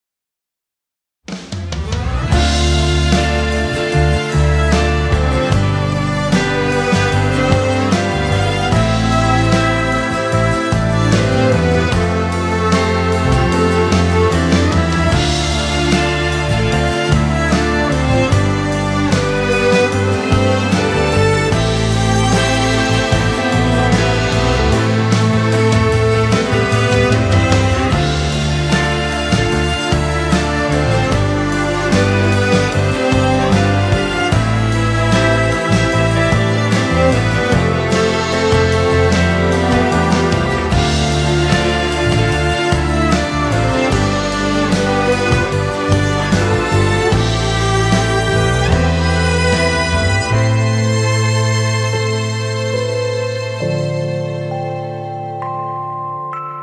tema dizi müziği, duygusal huzurlu rahatlatıcı fon müziği.